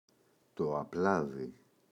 απλάδι, το [a’plaði] – ΔΠΗ